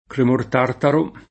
cremore [ krem 1 re ] s. m.